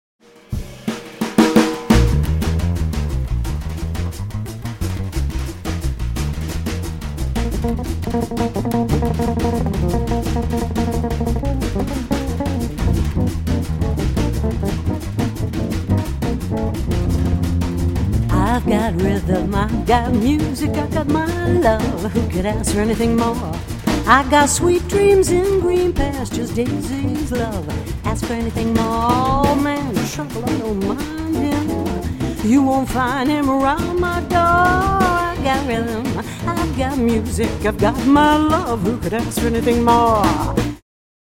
vocals/acoustic guitar
bass
acoustic/electric guitar
drums/bongos
violin